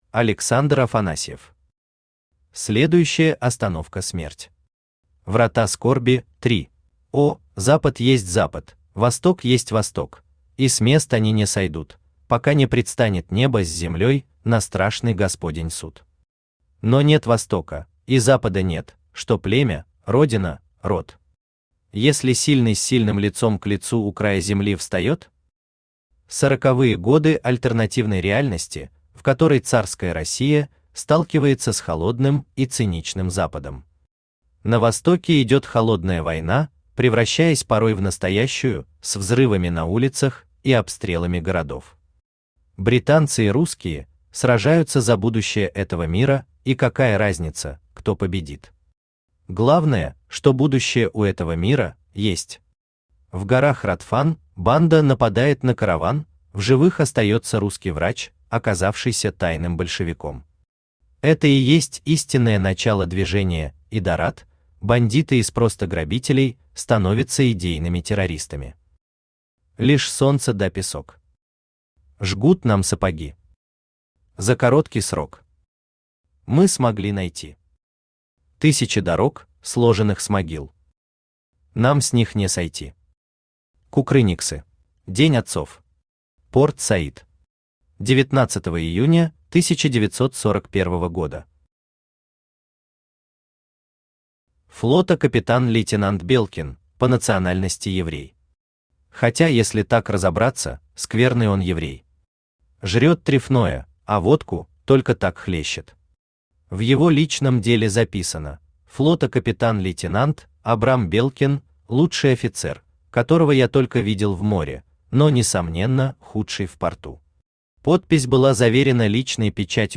Врата скорби – 3 Автор Александр Афанасьев Читает аудиокнигу Авточтец ЛитРес.